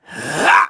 Crow-Vox_Casting1.wav